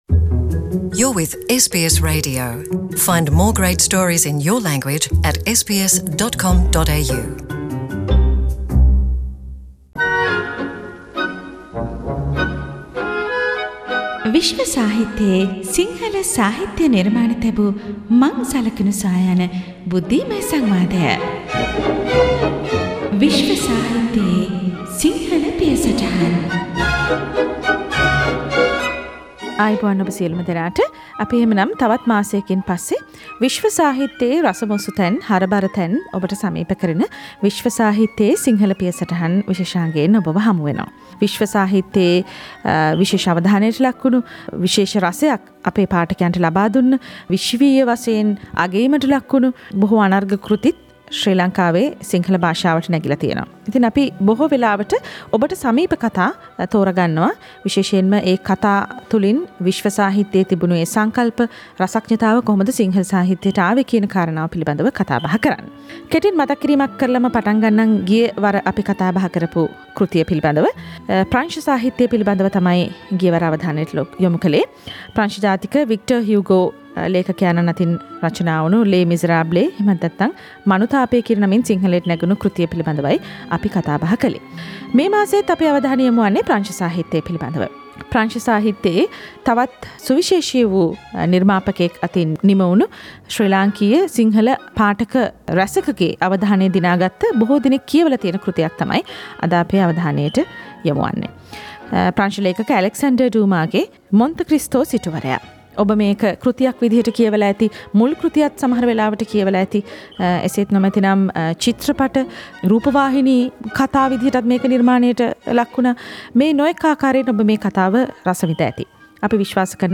SBS Sinhalese World literary discussion